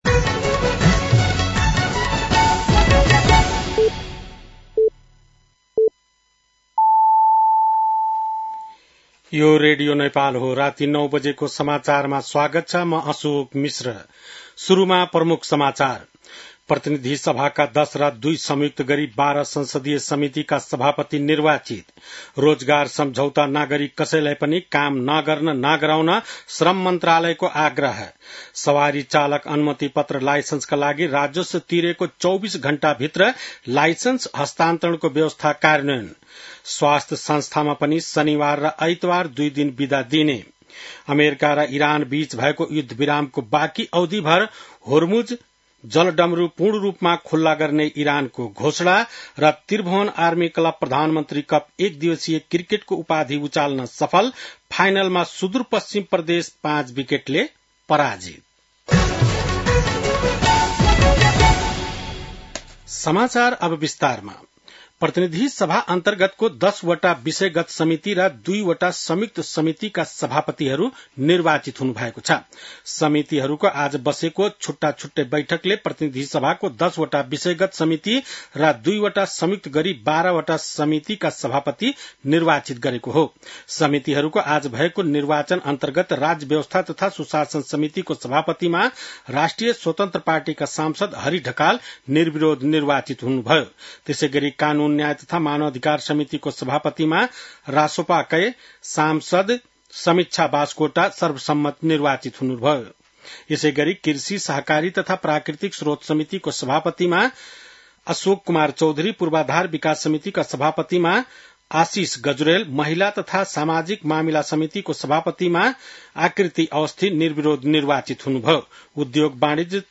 बेलुकी ९ बजेको नेपाली समाचार : ४ वैशाख , २०८३
9.-pm-nepali-news-.mp3